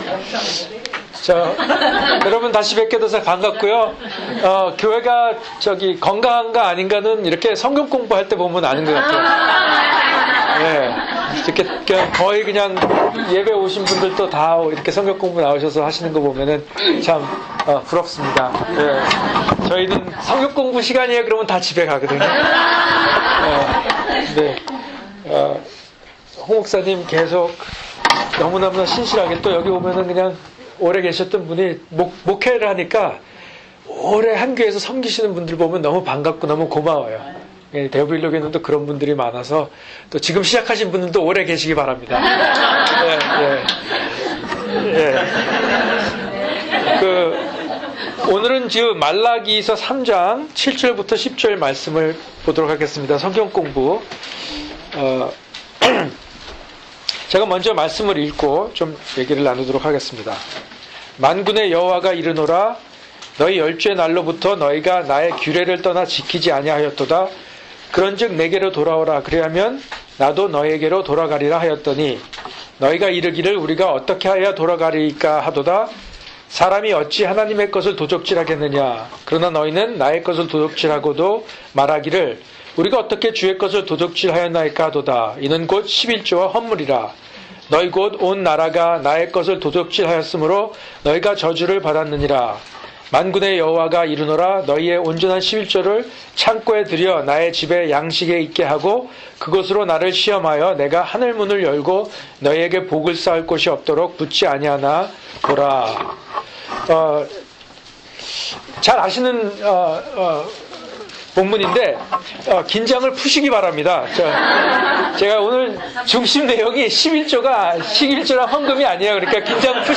[주일 설교] 사도행전 2:14-21